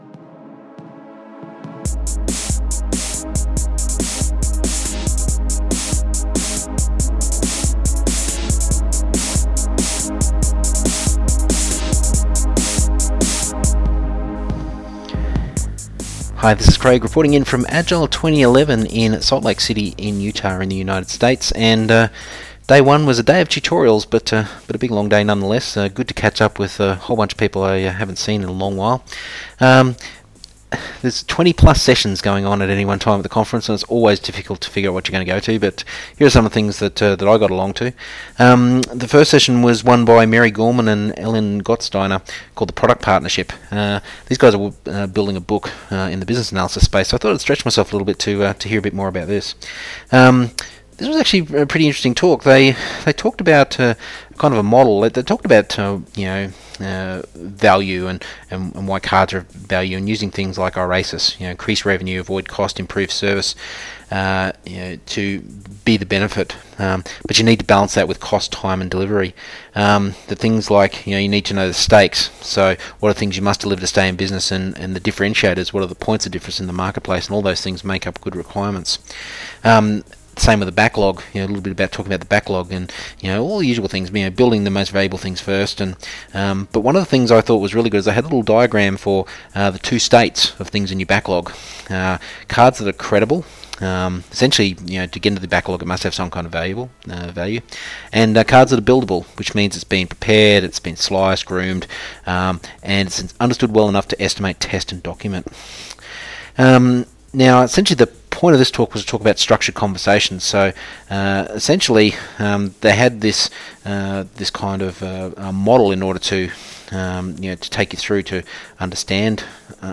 direct from the Agile 2011 Conference at Salt Lake City.